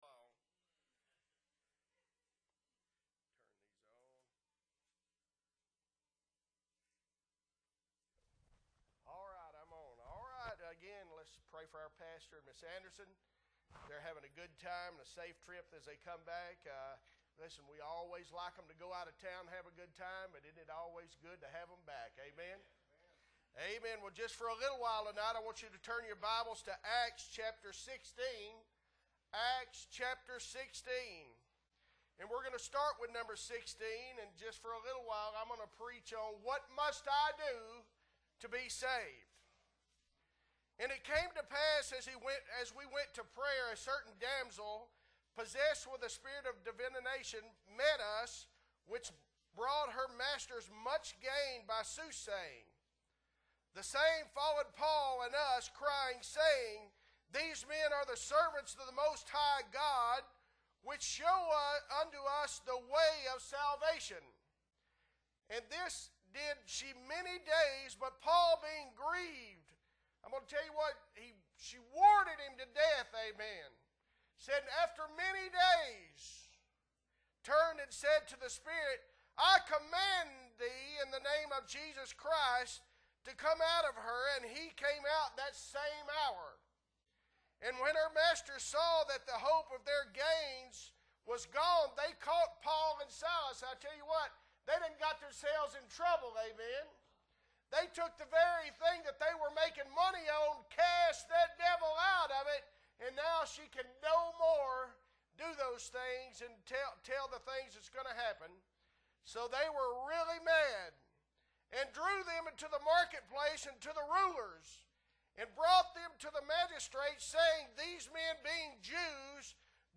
August 14, 2022 Sunday Evening Service - Appleby Baptist Church